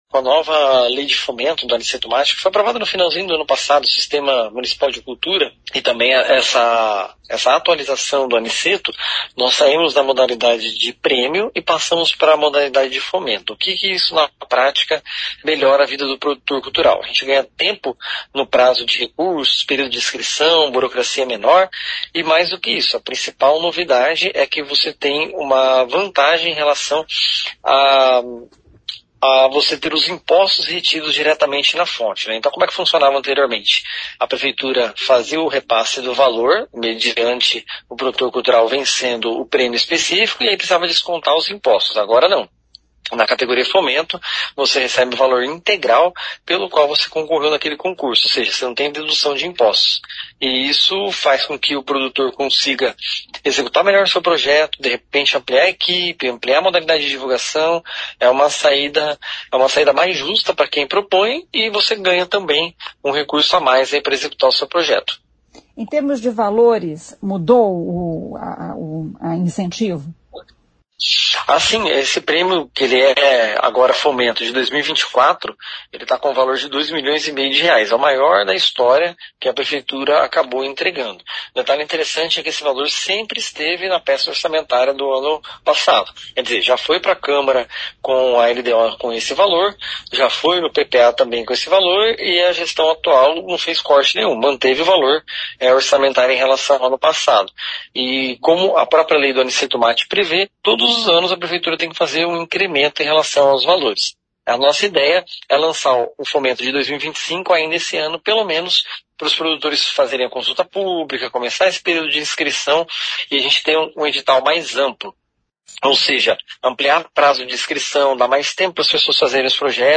O secretário de Cultura Tiago Valenciano explica as novidades.